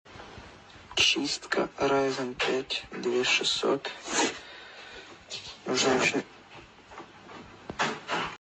I can’t stop laughing 😂🤣 sound effects free download